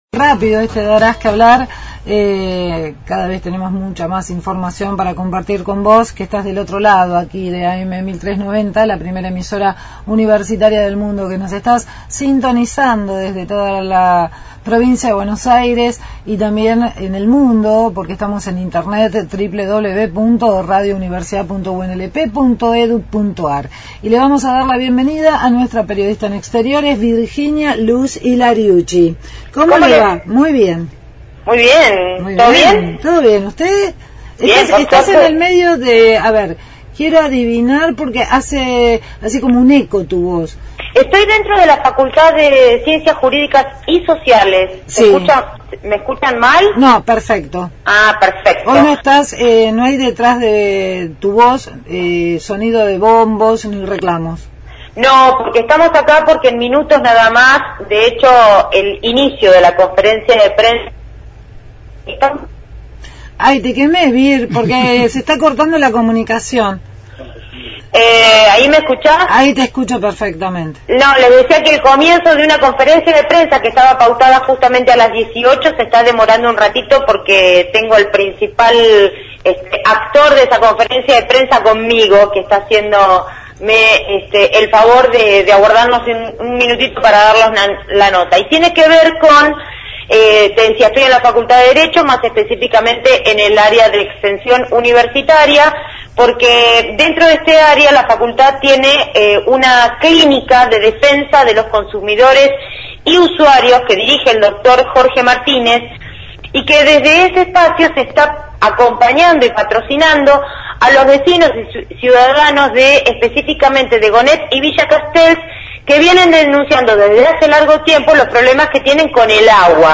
Conferencia por el agua no potable en Gonnet – Radio Universidad